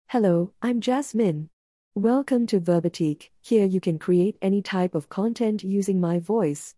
Jasmine — Female Singaporean English AI voice
Jasmine is a female AI voice for Singaporean English.
Voice sample
Listen to Jasmine's female Singaporean English voice.
Female
Jasmine delivers clear pronunciation with authentic Singaporean English intonation, making your content sound professionally produced.